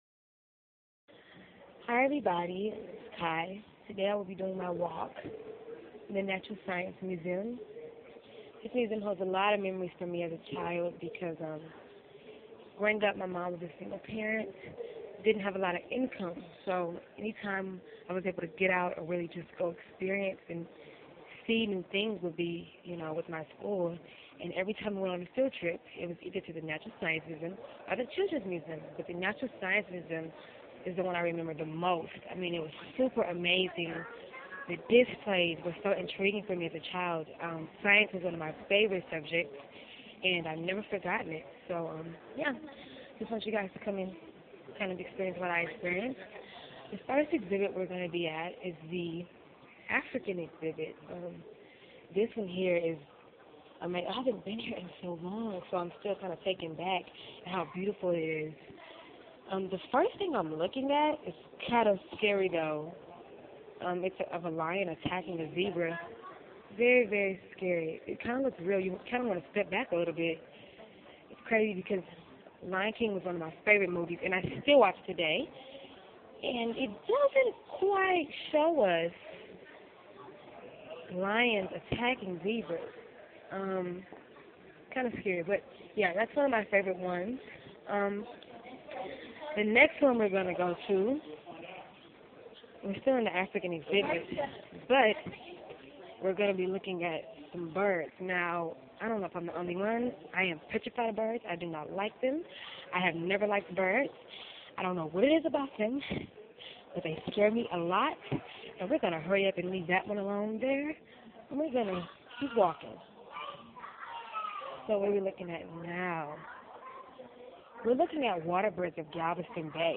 I starterd at the Top level of the museum , which was the african exhbit and work my way down to the very last level.